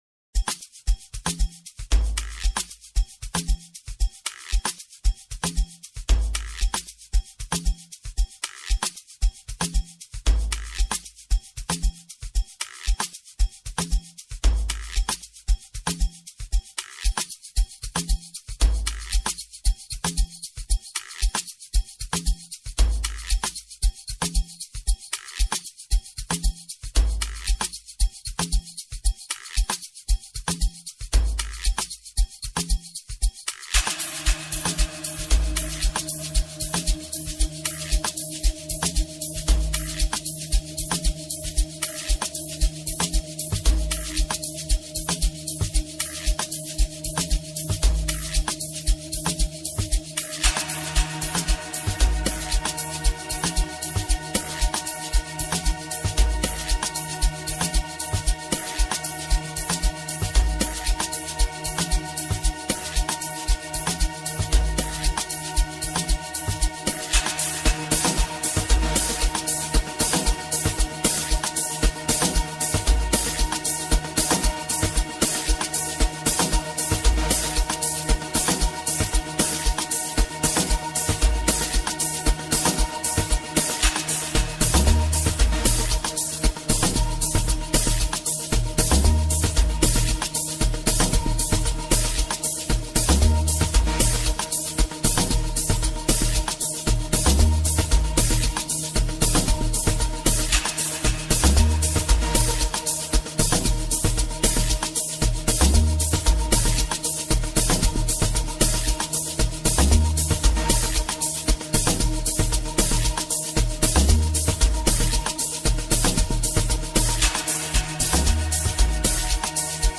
piano song
soulful flavor